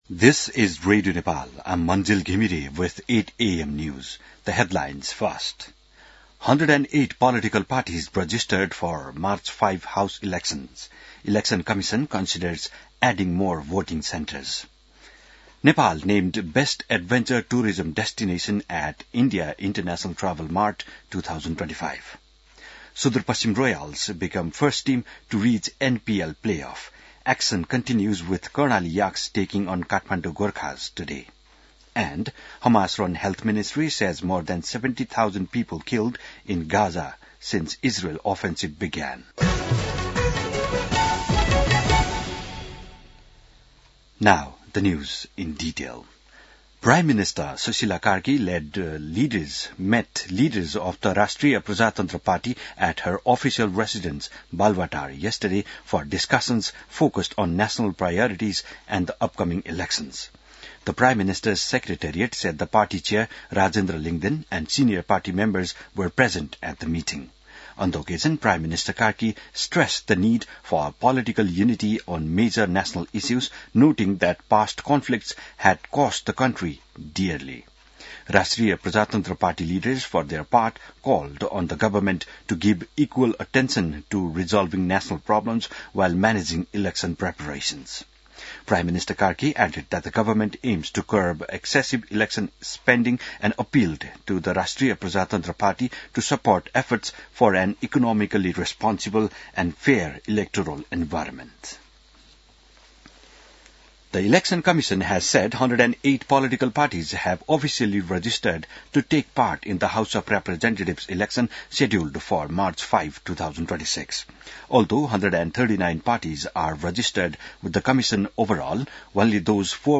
बिहान ८ बजेको अङ्ग्रेजी समाचार : १४ मंसिर , २०८२